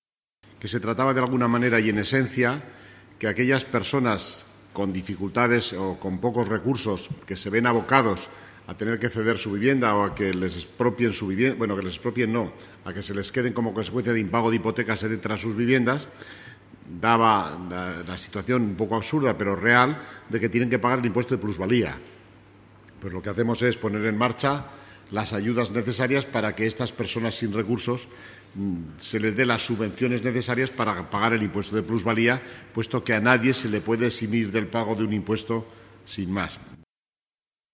Sobre este tema, el vicealcalde, Fernando Gimeno, ha manifestado: